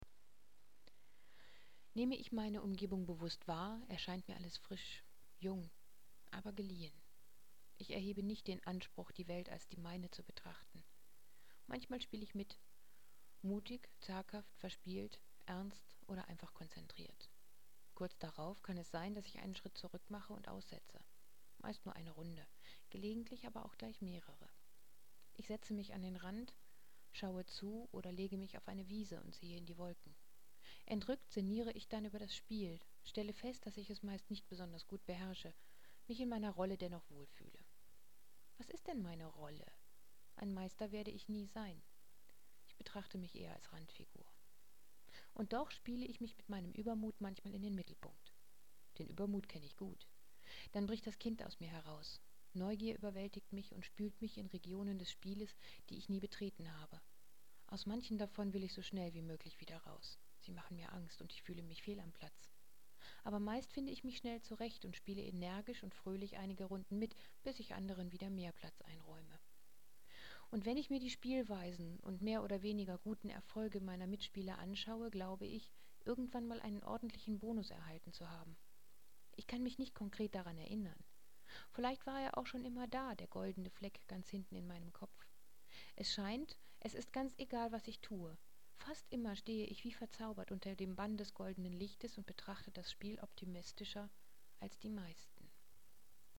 (Jaja ich weiß, es ist leise.)